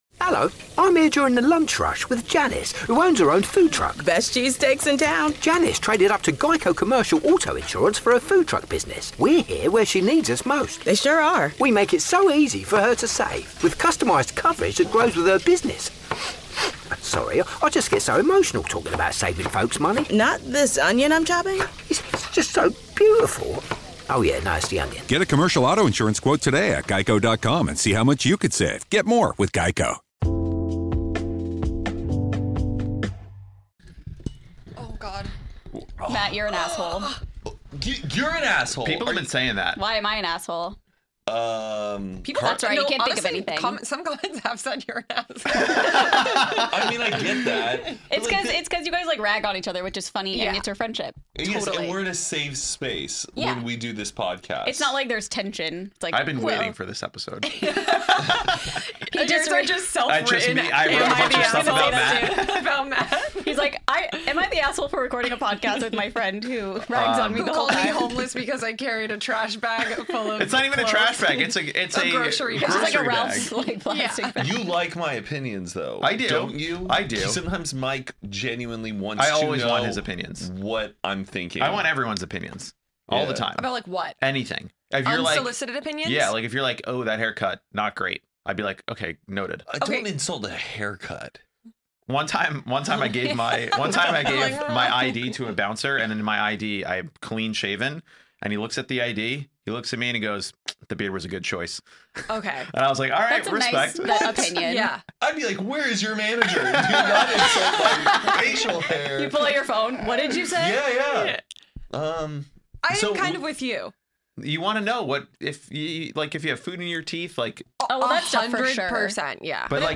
We explore the hilarious subreddit "Am I the A-Hole?" and have a lively debate about whether or not these situations are fair, reasonable, or insane!
The 4 hosts of Hoot & a Half and Only Friends have come together to create a supergroup show, where each week we will dive into and discuss, answer your questions, and more.